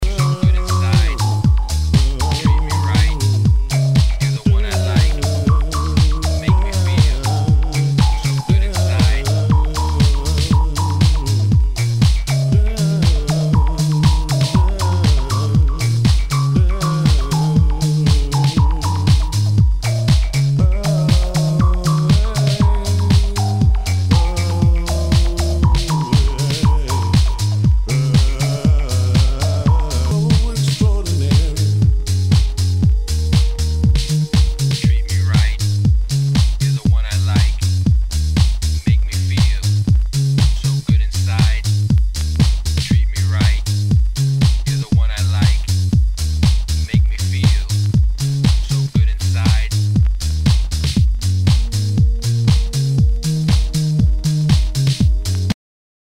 HOUSE/TECHNO/ELECTRO
ナイス！ディープ・ハウス・クラシック！
全体にチリノイズが入ります。